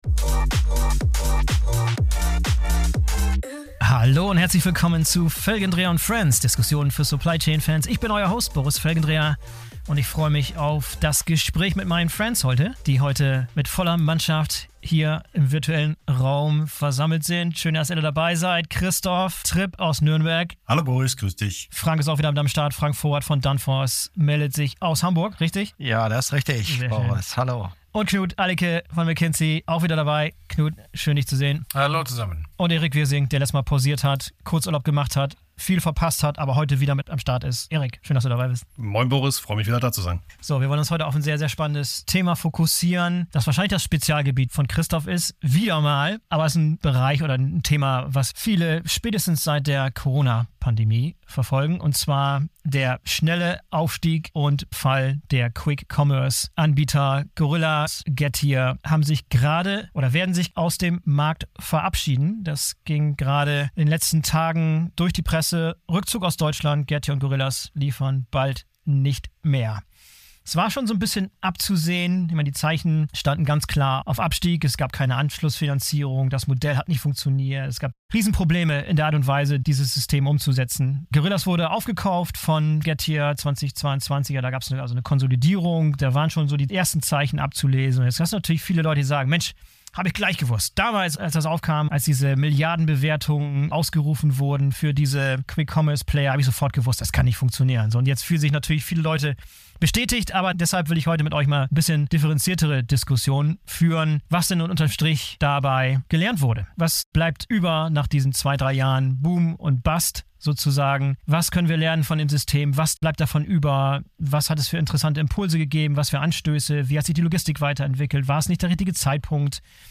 Wir diskutieren was schief gelaufen ist, woran das System der Schnelllieferungen letztlich gescheiter ist und welche Rolle die Logistik gespielt hat oder hätte spielen können. Wir werfen aber auch einen Blick darauf, welche neuen Impulse die Quick Commerce Player dem größeren E-Food-Bereich verliehen haben und was letztlich unterm Strich als Learning für die Zukunft bestehen bleibt.